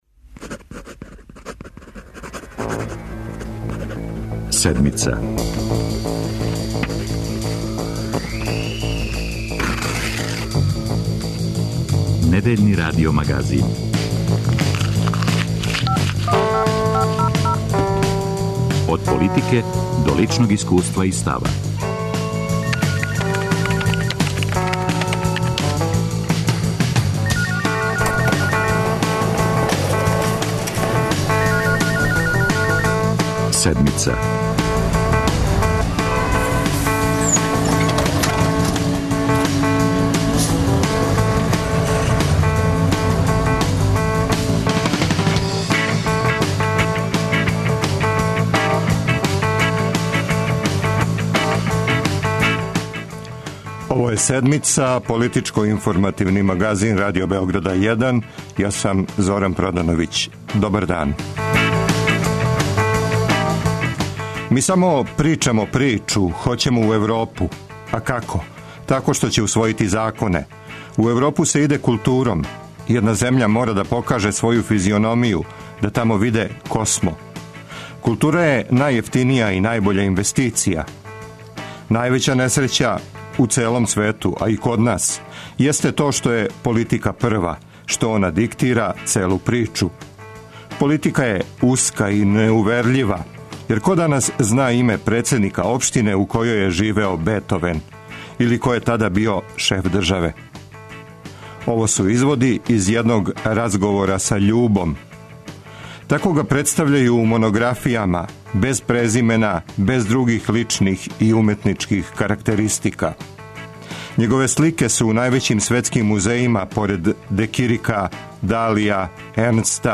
Гост емисије је један од највећих српских сликара Љуба Поповић. У Паризу га својатају и кажу – он је француски сликар српског порекла, а у Србији га оптужују да је националиста. Говори о политици, култури, уметности, оштро бескомпромисно и отворено...